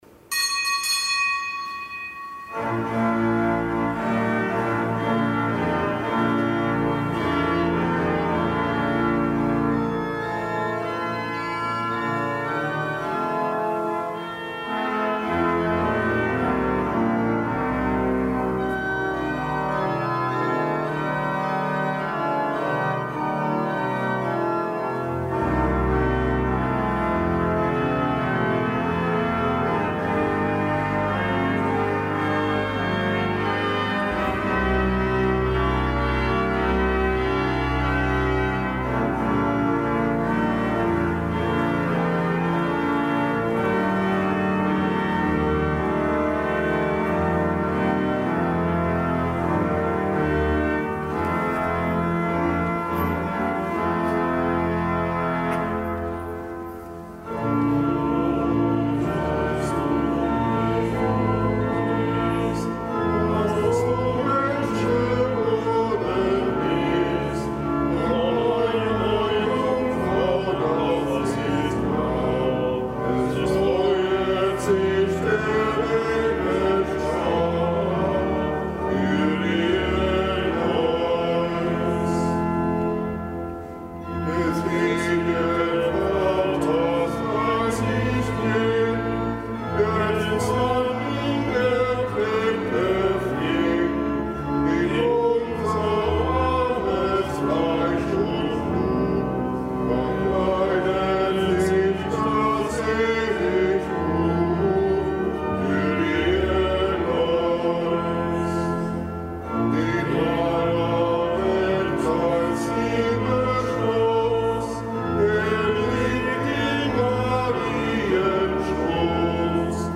Kapitelsmesse aus dem Kölner Dom am Samstag der Weihnachtszeit.